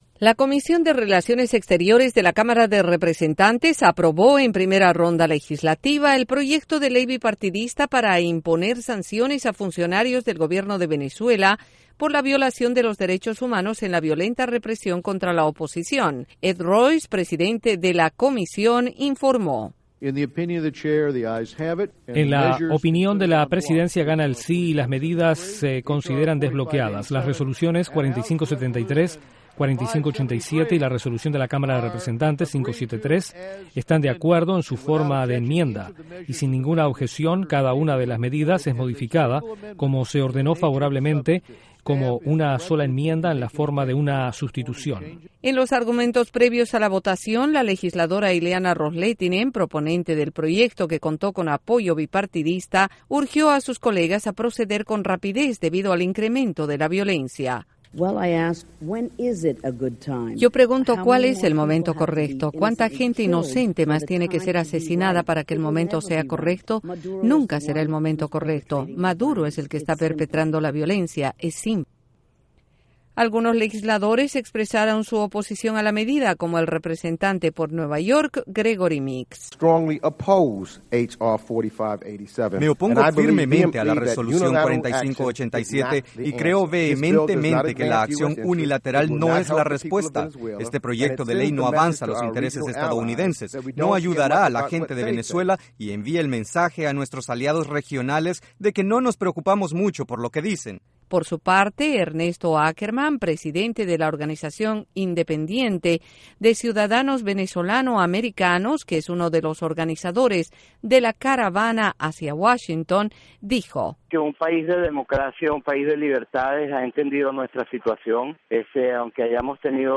Una comisión en el Congreso estadounidense aprueba, en primera instancia, una resolución para sancionar a funcionarios del gobierno de Venezuela involucrados en la violación de los derechos humanos. Desde la Voz de América en Washington DC informa